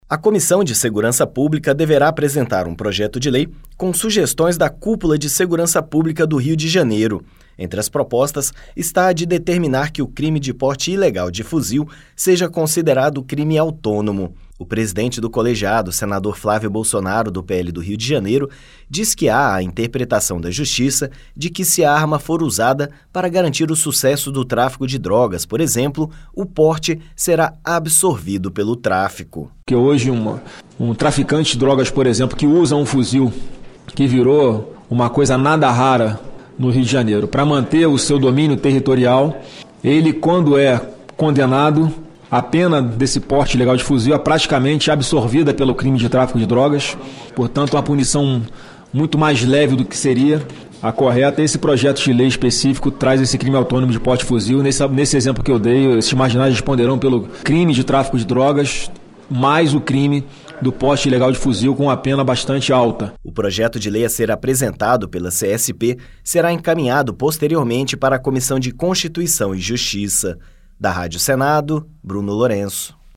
O presidente do colegiado, Flávio Bolsonaro (PL-RJ), diz que a ideia é tornar mais grave a punição para o porte desse tipo de armamento.